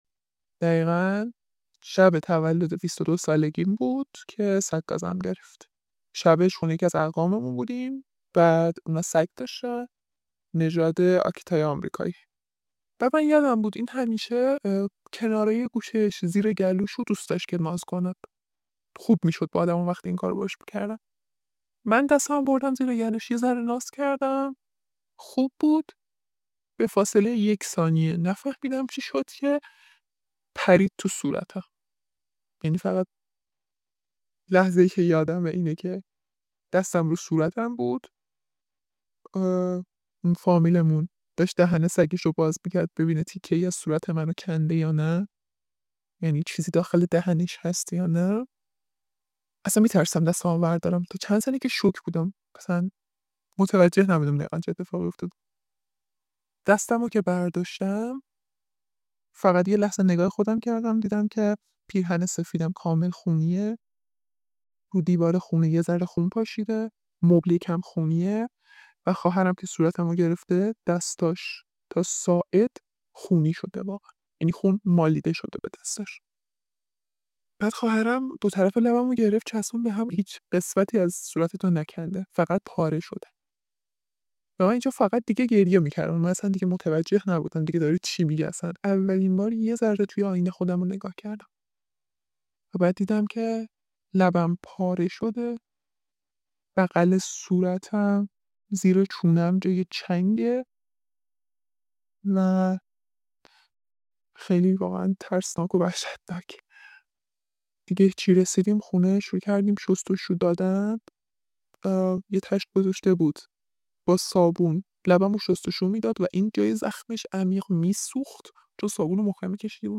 - صدایی که می‌شنوید با نرم‌افزار تغییر کرده است.